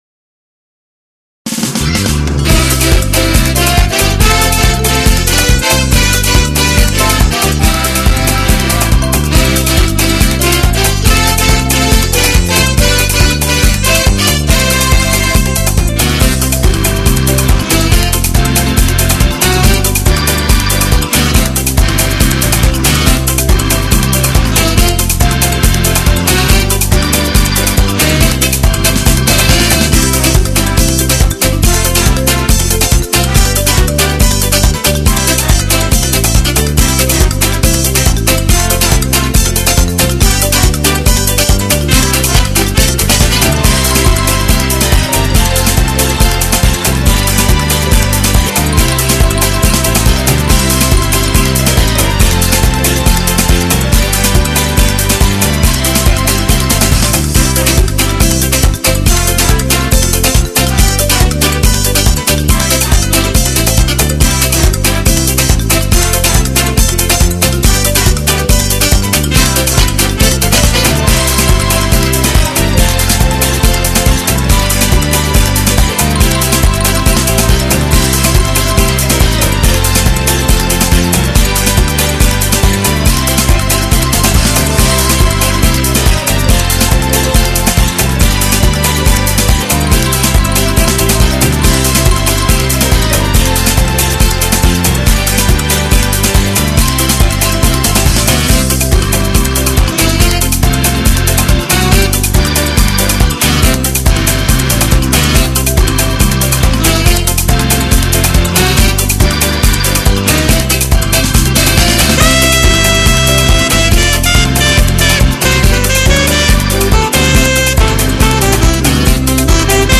Scarica la Base Mp3 (2,83 MB)